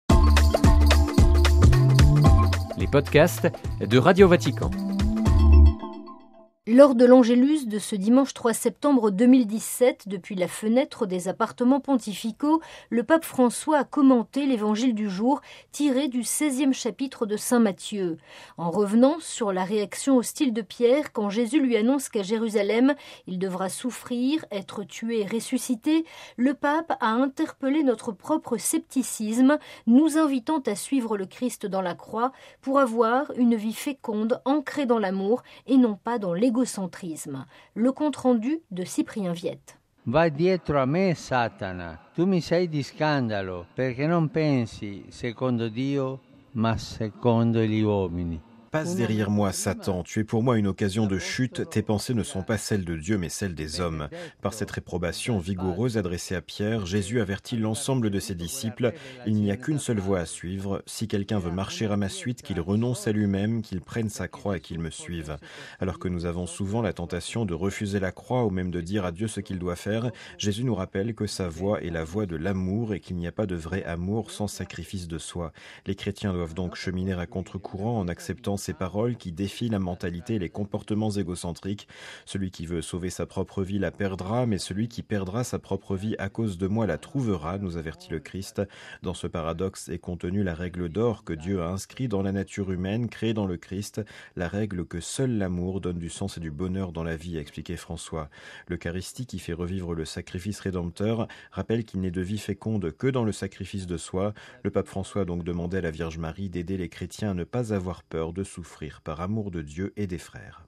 (RV) Lors de la prière de l’Angélus, ce dimanche 3 septembre 2017, depuis la fenêtre des appartements pontificaux, le Pape François a commenté l’Évangile du jour, tiré du 16e chapitre de Saint-Matthieu.